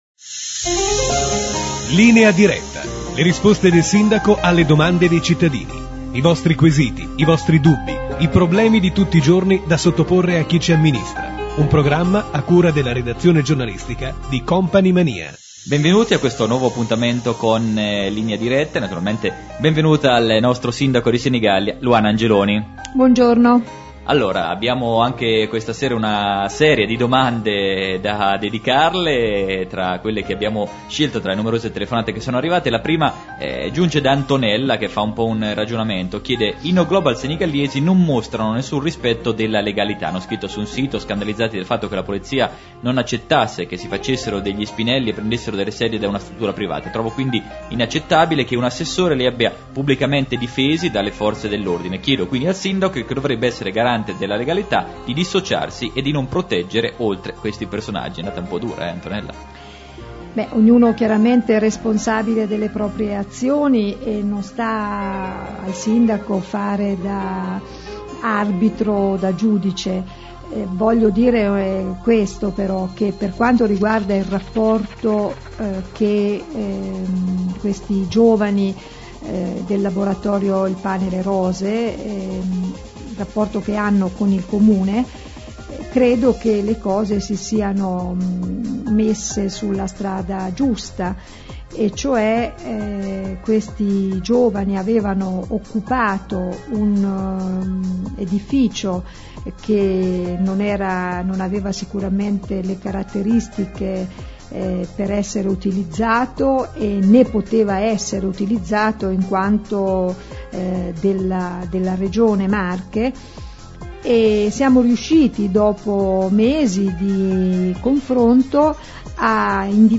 Scarica e ascolta Linea Diretta del 9/12/2004 : il programma radiofonico in cui il Sindaco Angeloni risponde alle domande dei senigalliesi. Il Sindaco risponde sui rapporti tra l’Amministrazione ed il C.S.A. Mezza Canaja; sulla partecipazione del Comune , per la prima volta, alle spese per le luci di Natale; festa di Capodanno in collaborazione con il Summer Jamboree; bonifica amianto area Sacelit, e la proprensione della Giunta ad ascoltare i cittadini.